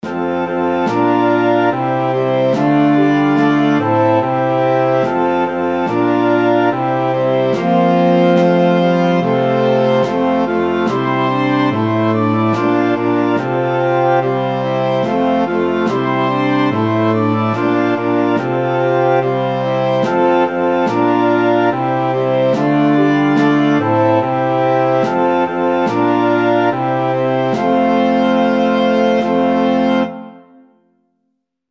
Kolędy